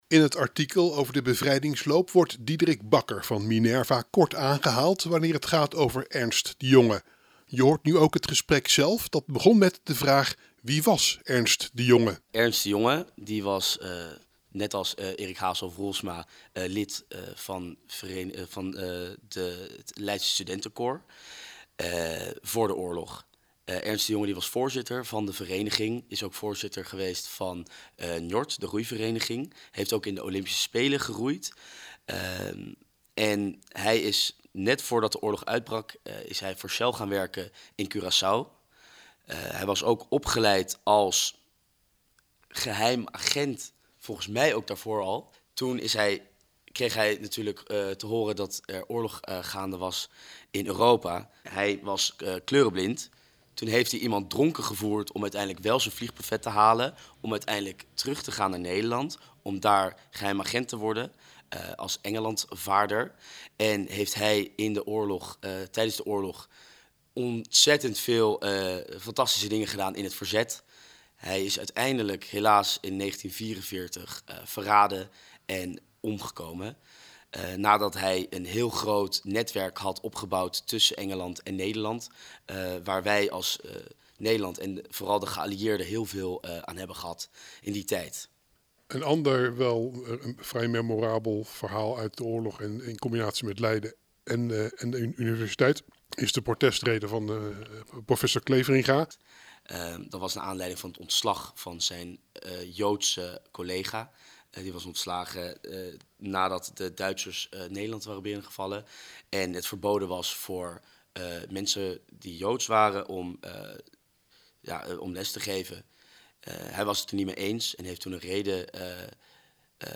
En je hoort een gedeelte van het optreden van Musicalgezelschap Otis in de Pieterskerk.